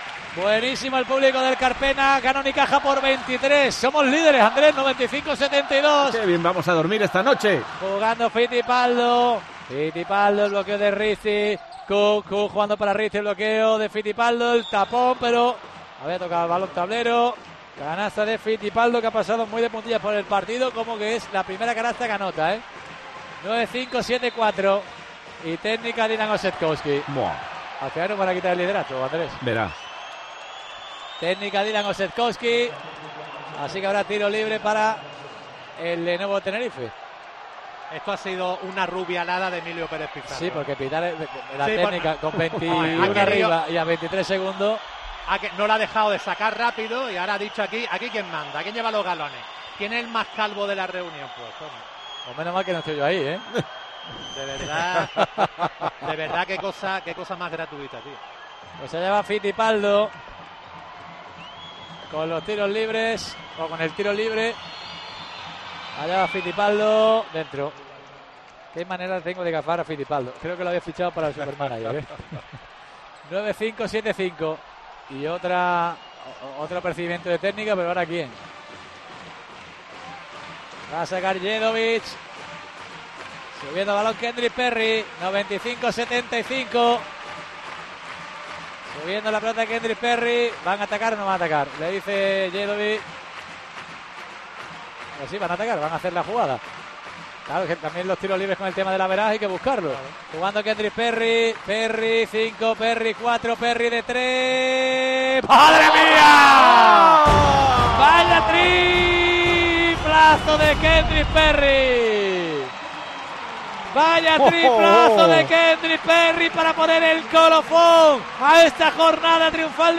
BALONCESTO
Incidencias: Partido correspondiente a la primera jornada de Liga Endesa disputado en el Palacio de los Deportes Martín Carpena de Málaga ante 9.022 espectadores.